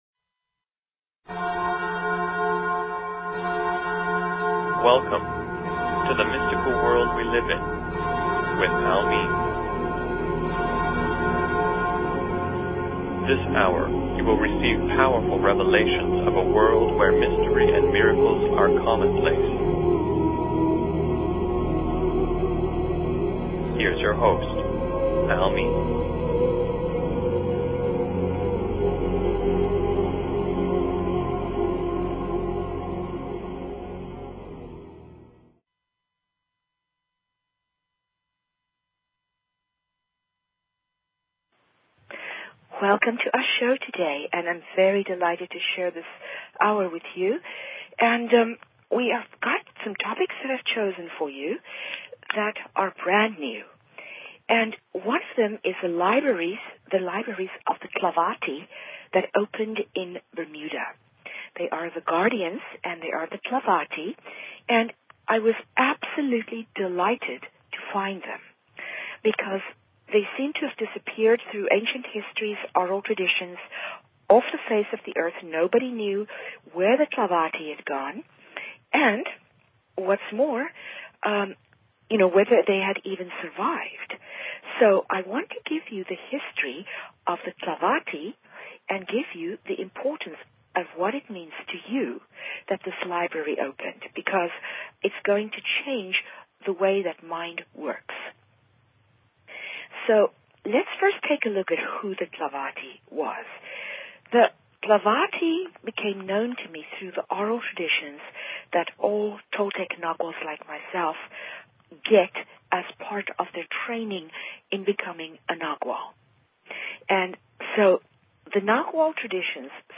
Talk Show Episode, Audio Podcast, The_Mystical_World_we_live_in and Courtesy of BBS Radio on , show guests , about , categorized as